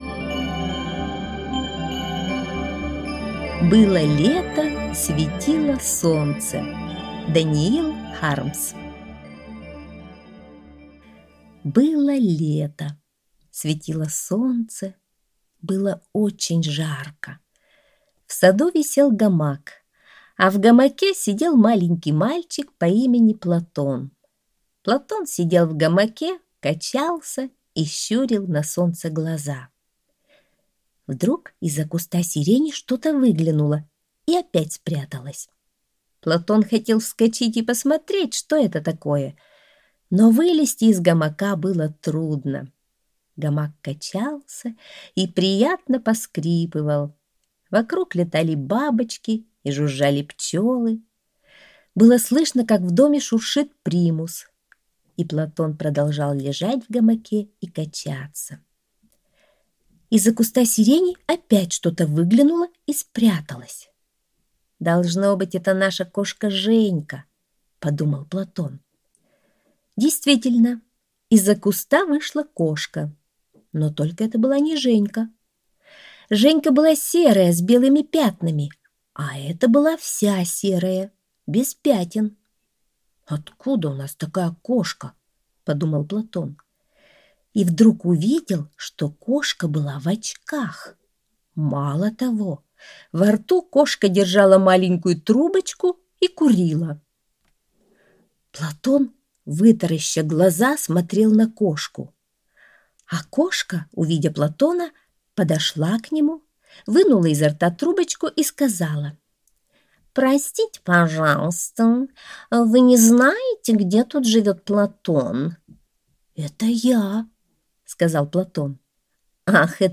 Аудиосказка «Было лето. Светило солнце…»